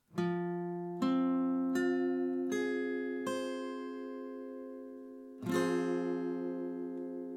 Der f-Moll-Akkord besteht aus den drei Tönen: F, As und C, die auch als Dreiklang bezeichnet werden.
f-Moll (Barré, A-Saite)
F-Moll-Akkord, Barre (A), Gitarre
F-Moll-Barre-A.mp3